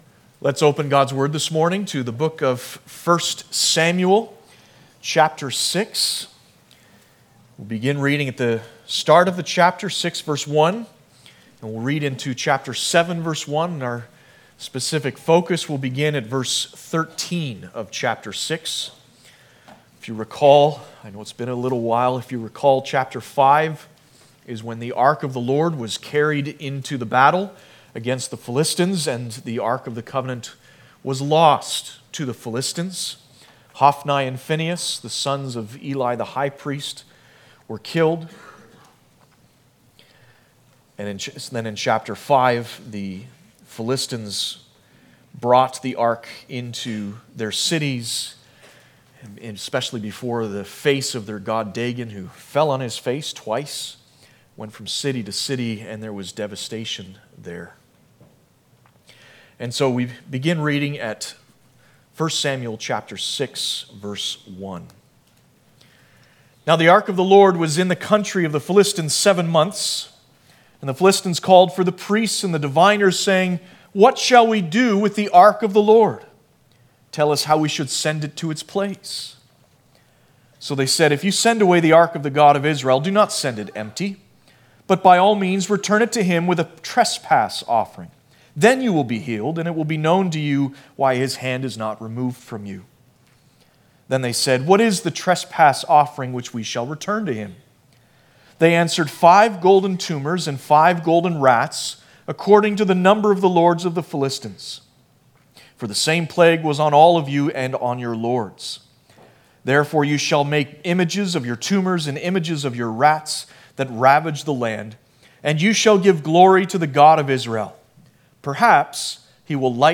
Passage: 1 Samuel 6:1-7:1 Service Type: Sunday Morning